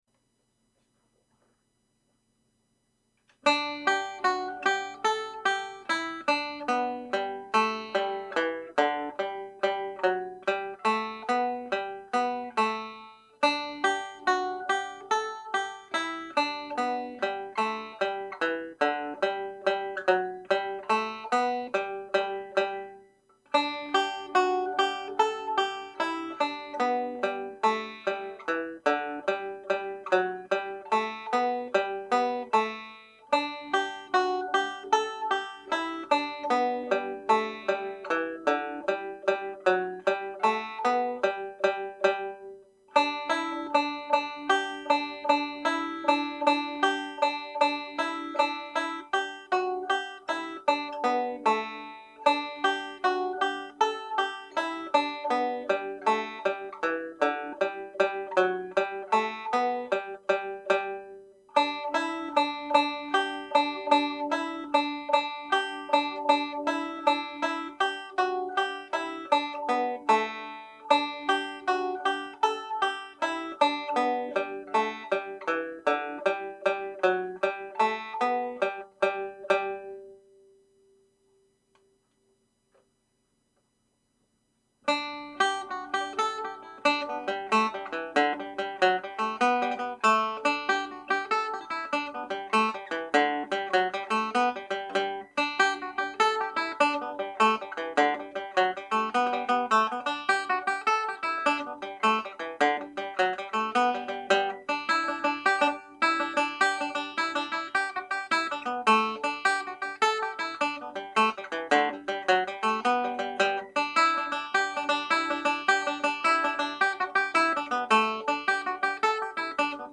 Jigs
(G Major)